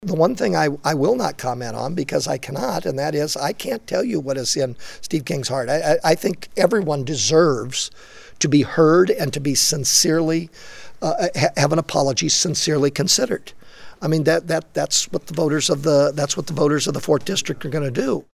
King today (Friday) said on the House floor that he is an advocate for the values of western civilization.